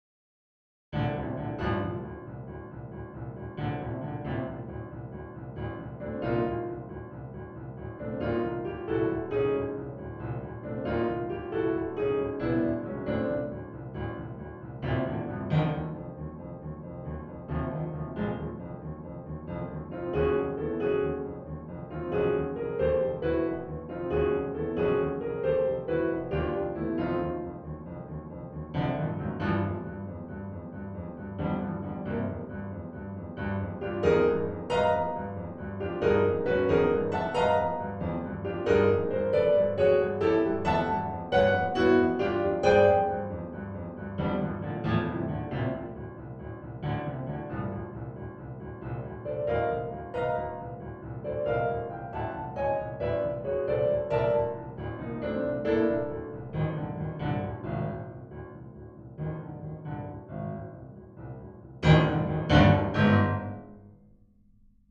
A collection of short solo piano pieces for the intermediate to advanced high school piano student or college piano major.